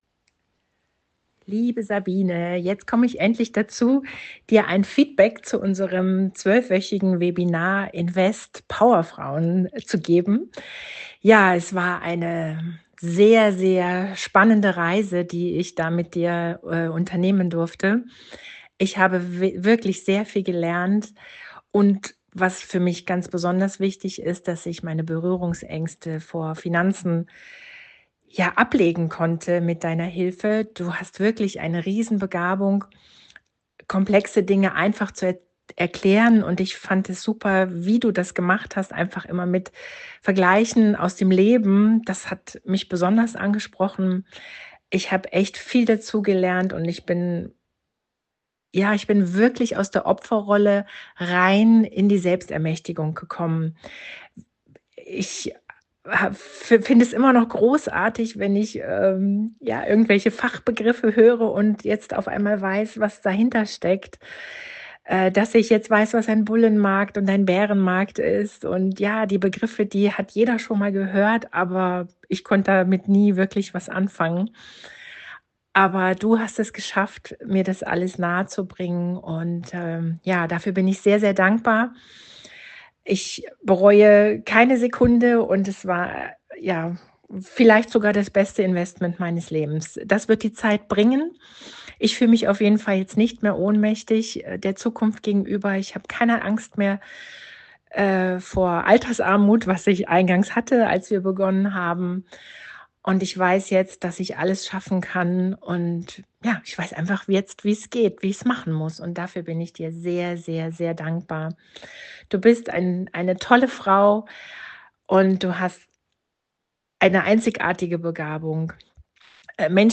Kundenstimmen